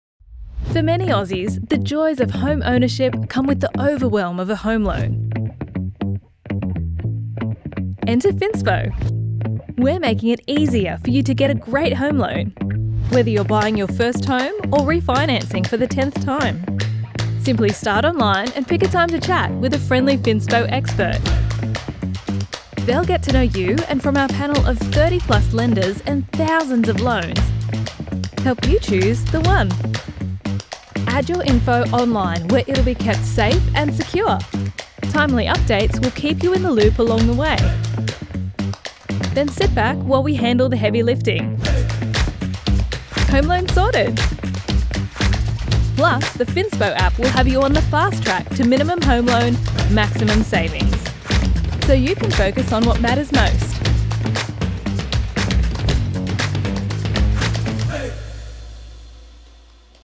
Female
English (Australian), English (Neutral - Mid Trans Atlantic)
Explainer Videos